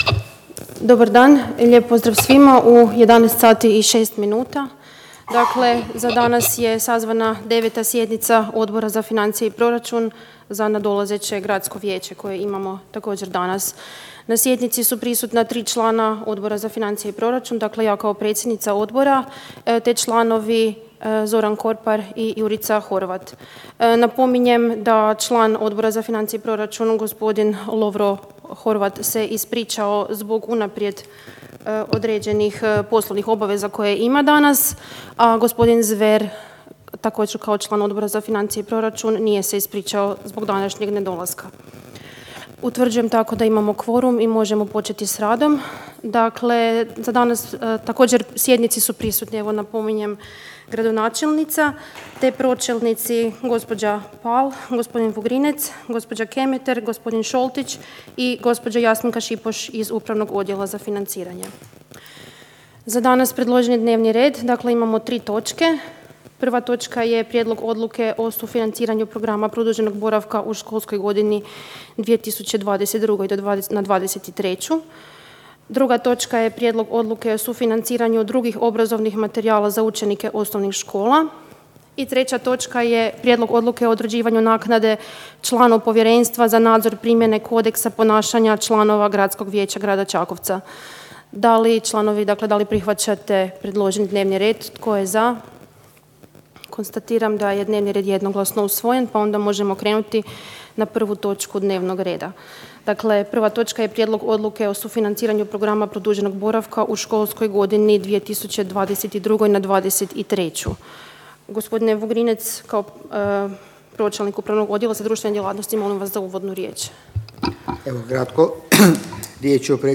Obavještavam Vas da će se 9. sjednica Odbora za financije i proračun Gradskog vijeća Grada Čakovca održati dana 24. lipnja 2022. (petak), u 11:00 sati, u gradskoj vijećnici Grada Čakovca.